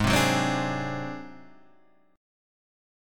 G#7b5 chord